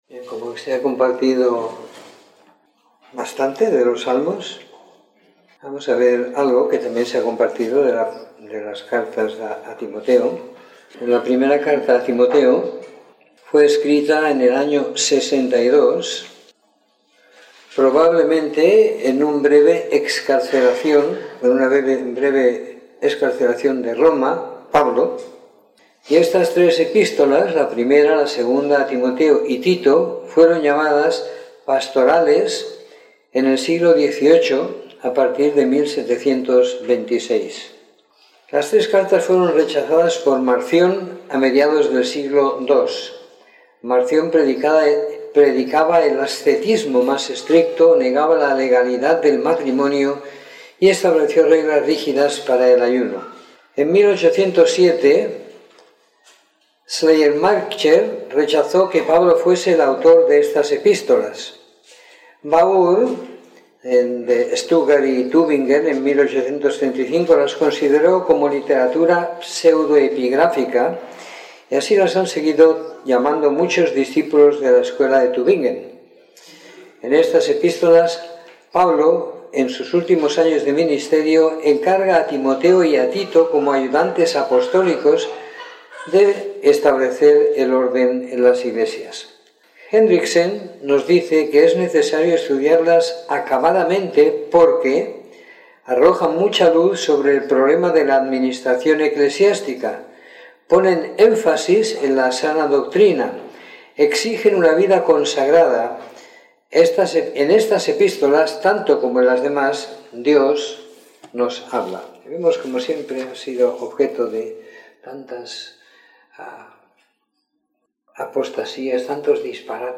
Comentario en los libros de 1ªTimoteo 1 - Filemón y Salmos 111-119 siguiendo la lectura programada para cada semana del año que tenemos en la congregación en Sant Pere de Ribes.